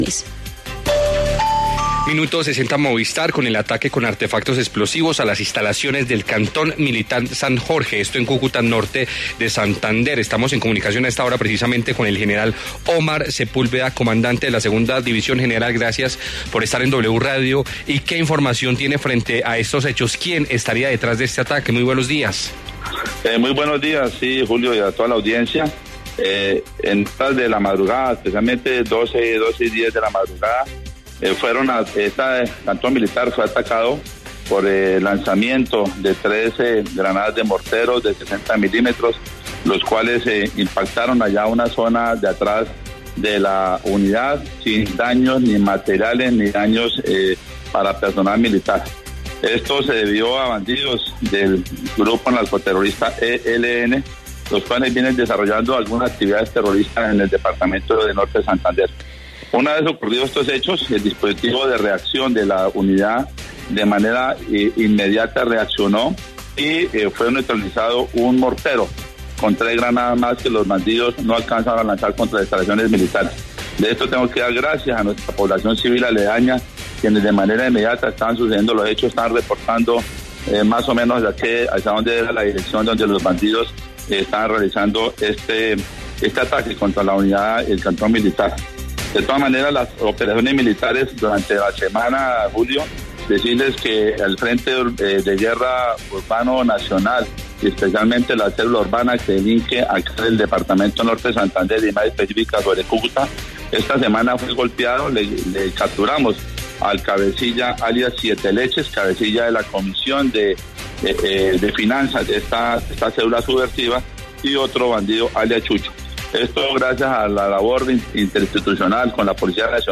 En diálogo con La W, el general Omar Sepúlveda, comandante de la Segunda División General, explicó quién podría estar detrás del atentado contra las instalaciones al Cantón Militar San Jorge.